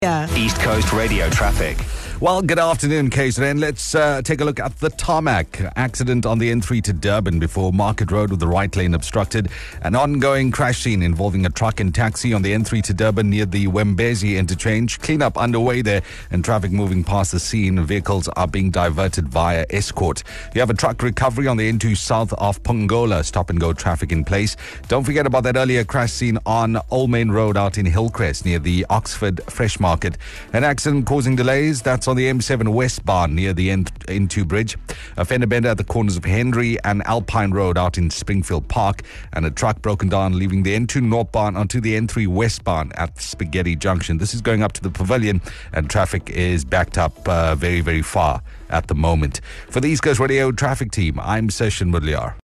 Here's your latest East Coast Radio Traffic Report.